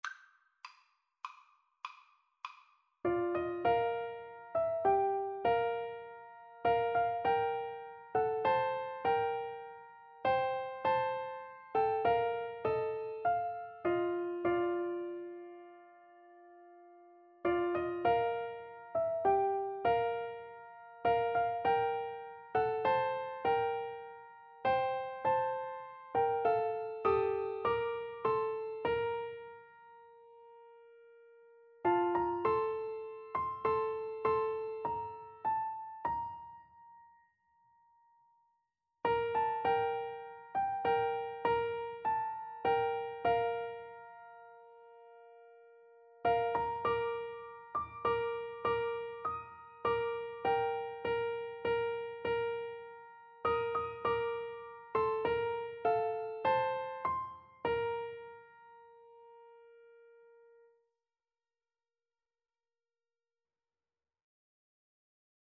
Free Sheet music for Piano Four Hands (Piano Duet)
6/8 (View more 6/8 Music)
Classical (View more Classical Piano Duet Music)